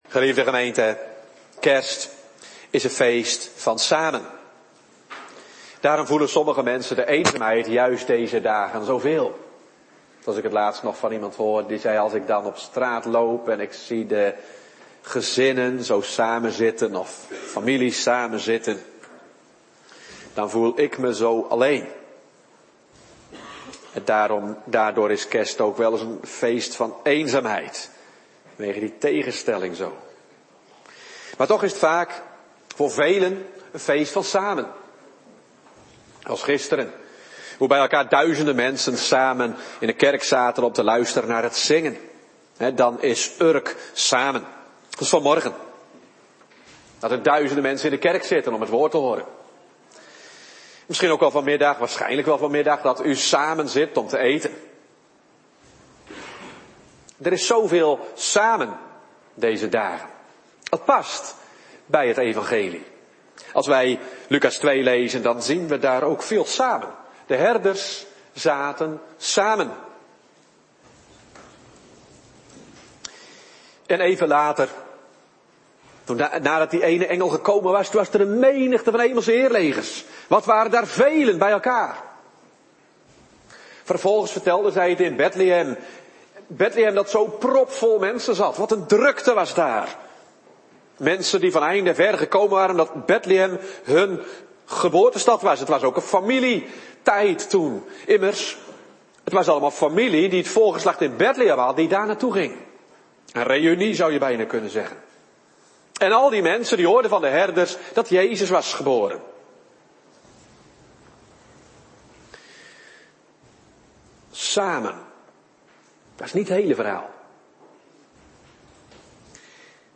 “Kerstfeest voor Jozef” Predikant